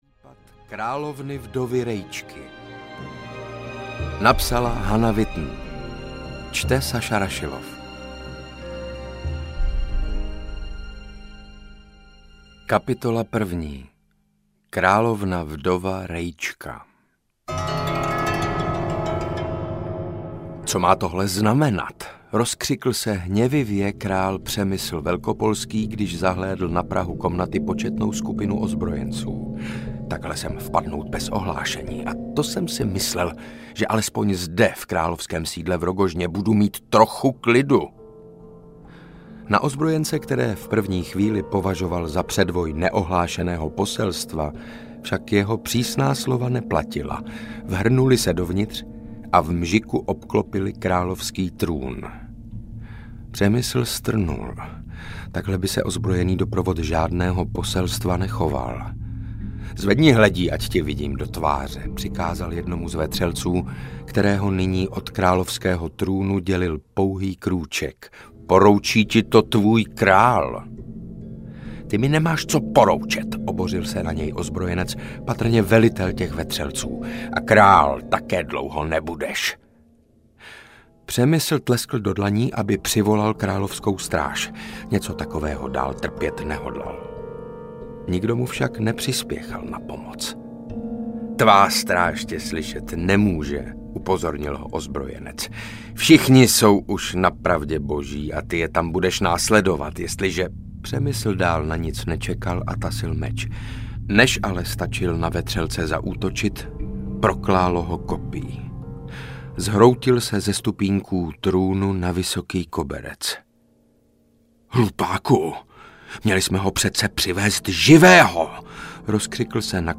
Adam ze Zbraslavi a případ královny vdovy Rejčky audiokniha
Ukázka z knihy
• InterpretSaša Rašilov